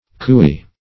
Cooey \Coo"ey\, Cooee \Coo"ee\, n. [Of imitative origin.]
Australian colonists. In the actual call the first syllable
is much prolonged (k[=oo]"-) and the second ends in a shrill,